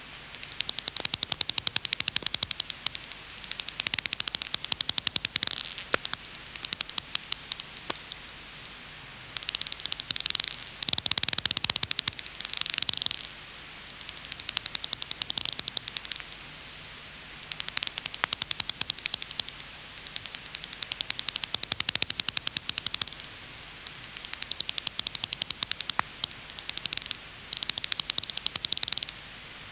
Sonothèque Chauves-souris Souterweb spéléo
myotis.daubentoni.wav